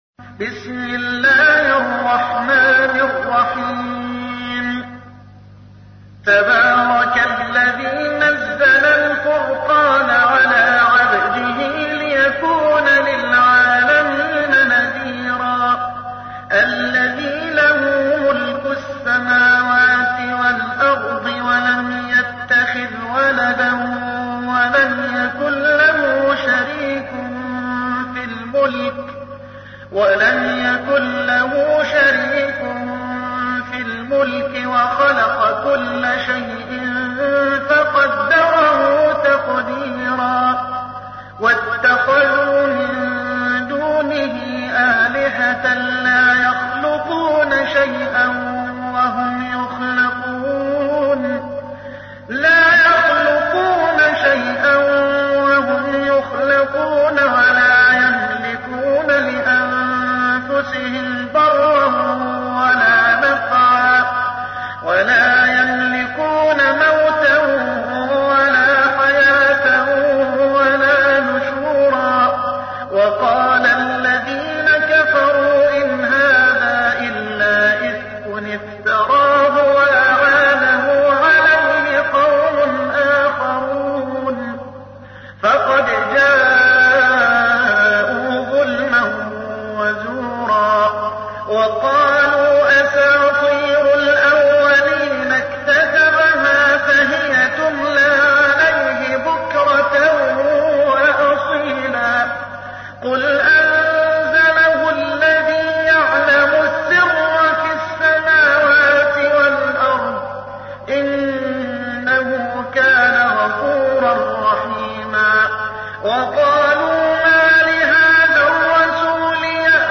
25. سورة الفرقان / القارئ